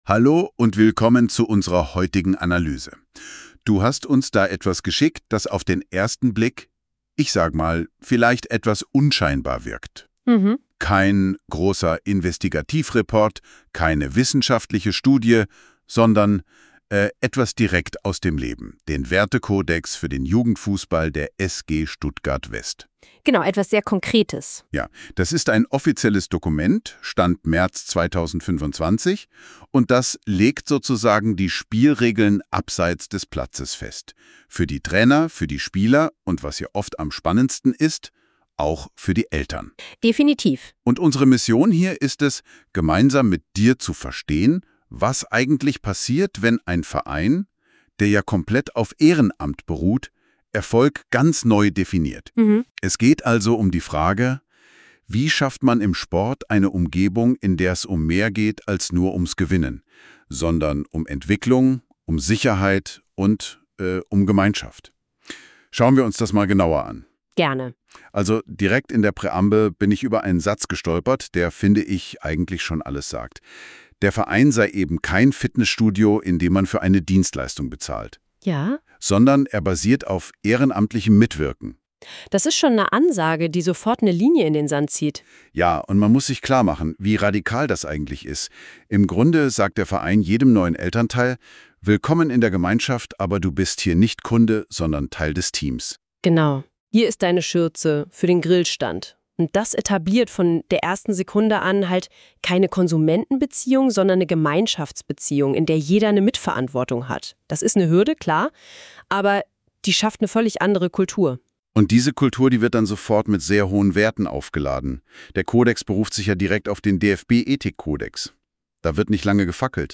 Deshalb haben wir einen Versuch gestartet und unseren Wertekodex von einer KI zu einem Podcast verarbeiten lassen.